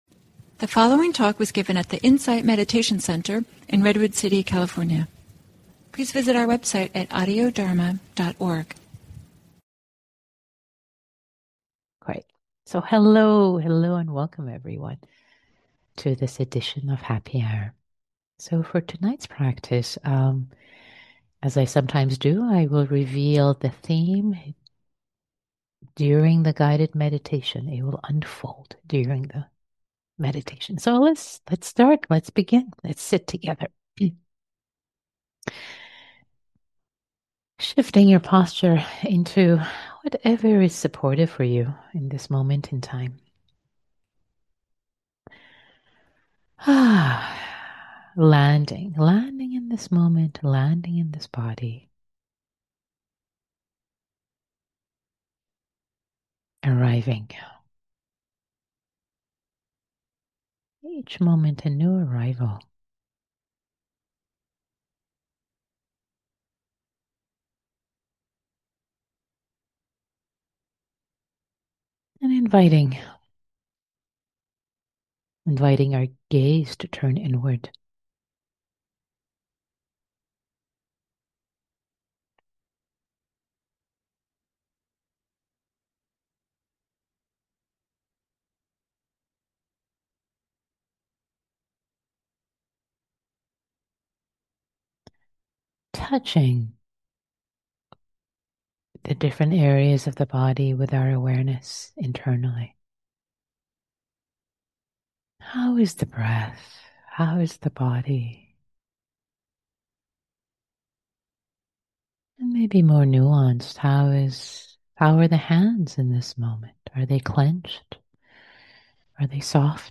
at the Insight Meditation Center in Redwood City, CA